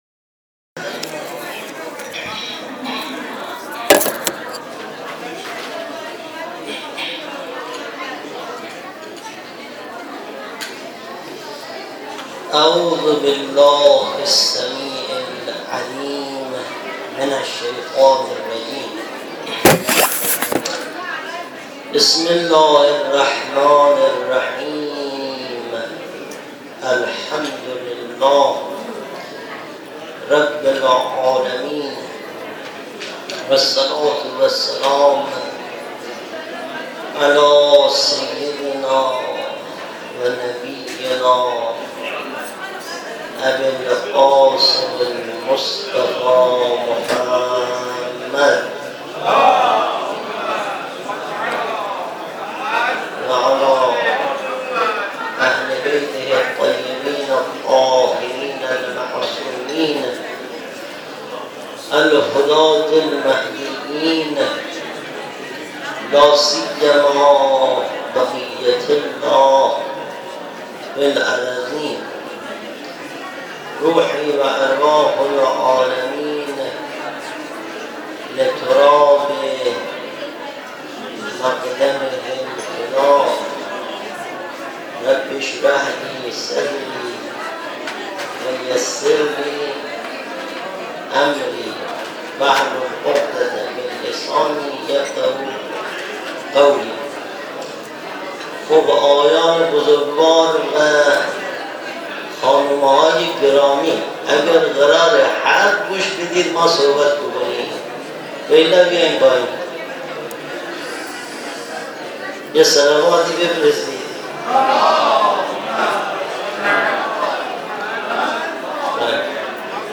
صوت سخنرانی لینک دانلود